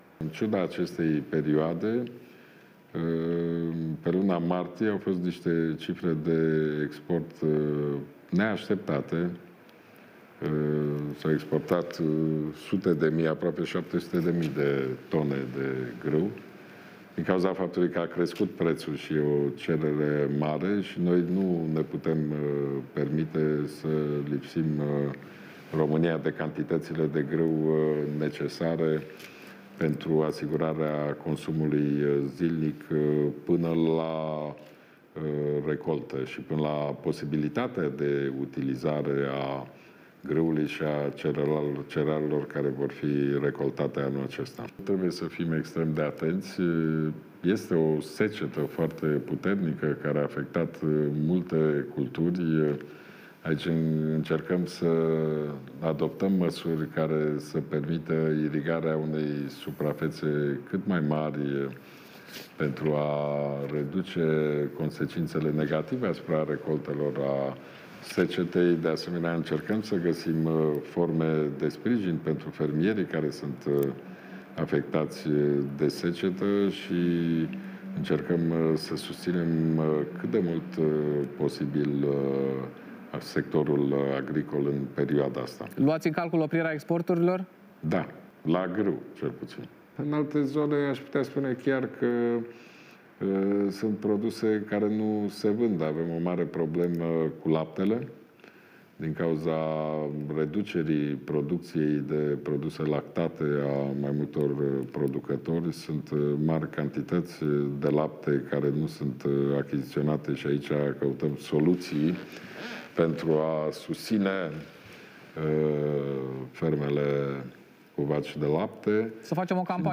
“Noi nu ne putem permite să lipsim România de cantităţile de grâu necesare pentru asigurarea consumului zilnic până la recoltă şi până la posibilitatea de folosire a grâului şi cerealelor de la recolta din anul acesta”, a declarat Ludovic Orban, joi seară, la televiziunea publică.